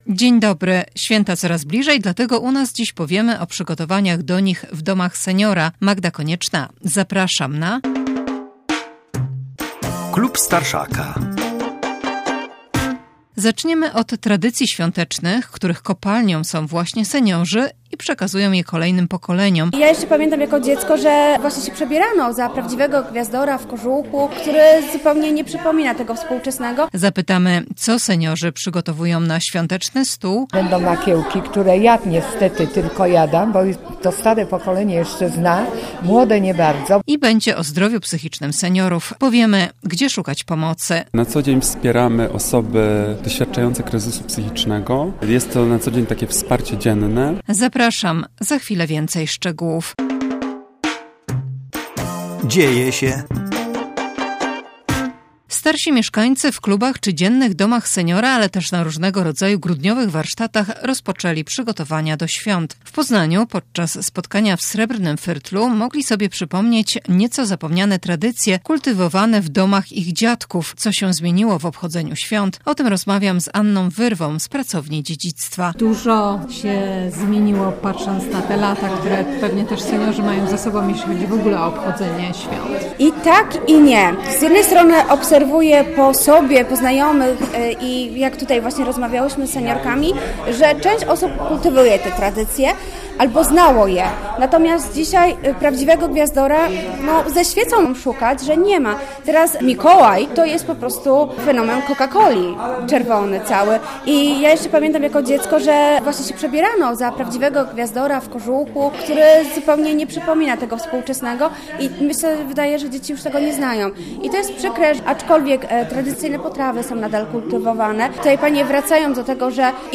Jakie świąteczne zwyczaje przetrwały - o tym w naszym programie powiedzą seniorzy. Zdradzą też, co będzie na ich stołach w święta.